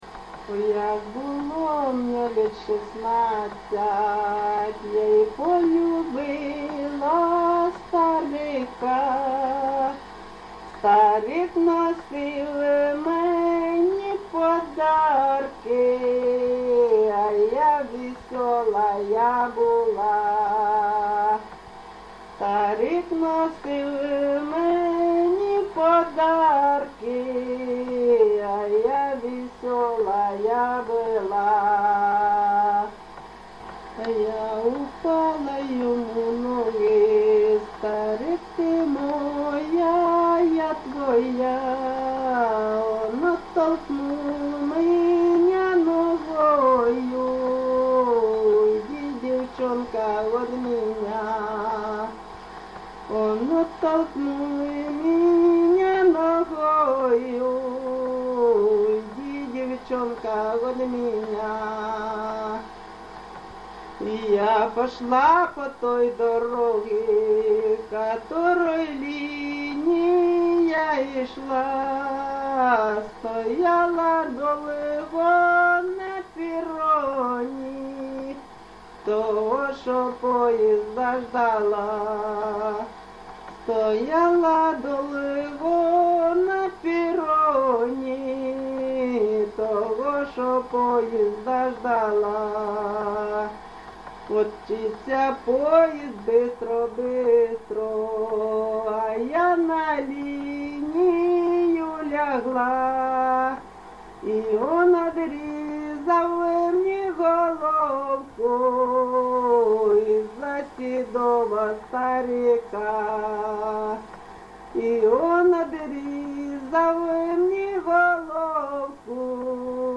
ЖанрБалади, Сучасні пісні та новотвори
Місце записус. Олександро-Калинове, Костянтинівський (Краматорський) район, Донецька обл., Україна, Слобожанщина